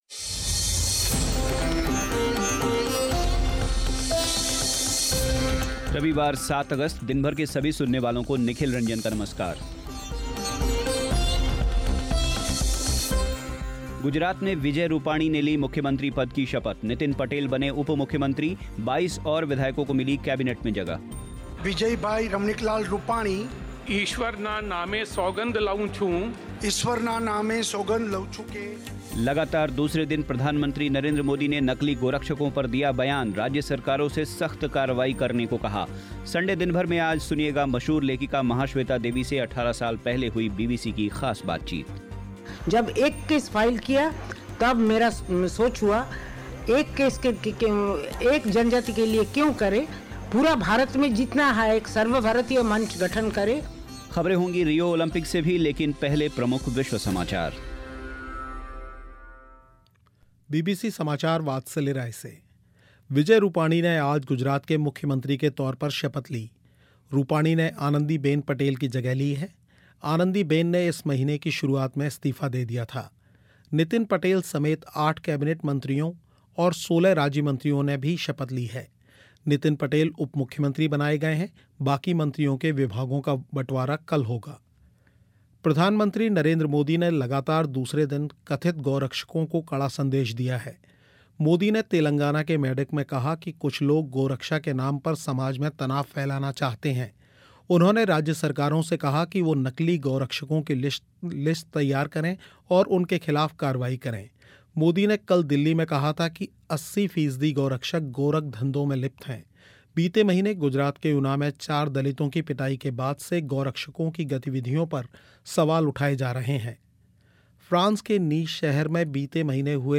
संडे दिन भर में आज सुनिएगा मशहूर लेखिका महाश्वेता देवी से 18 साल पहले हुई बीबीसी की ख़ास बातचीत